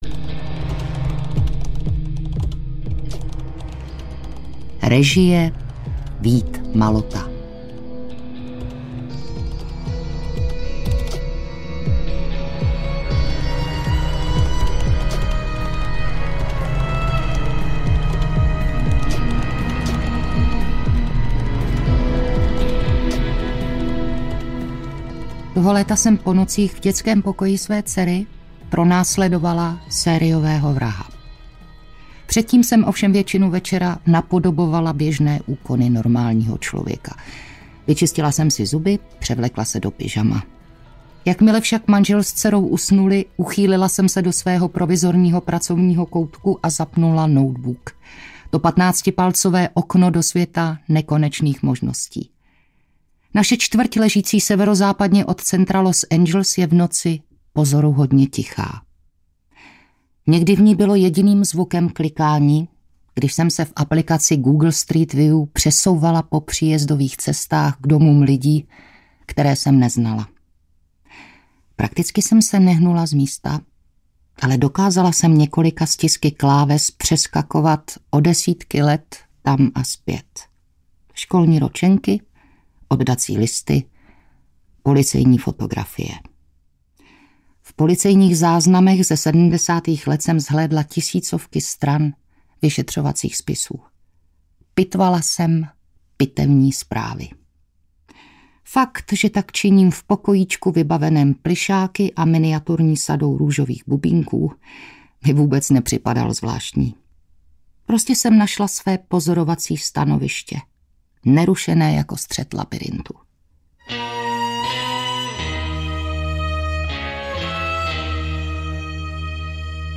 Zmizím ve tmě audiokniha
Ukázka z knihy